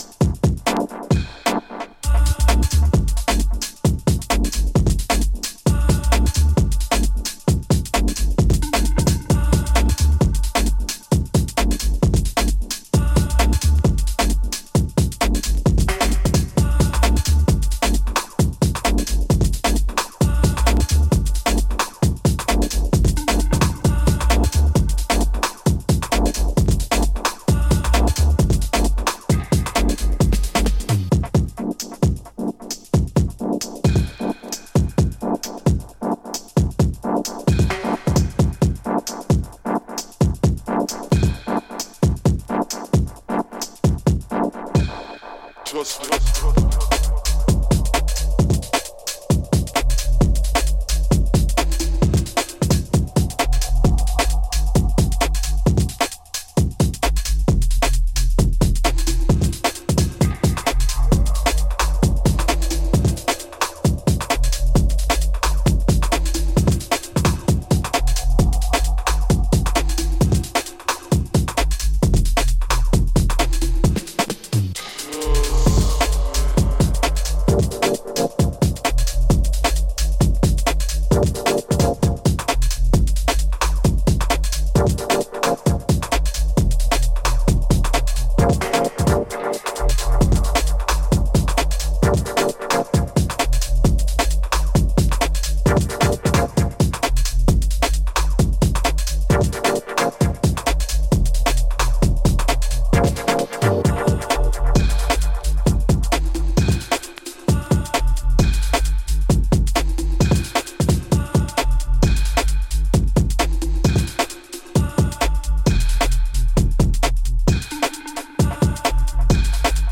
2ステップUKGとダブテクノが融合した132BPM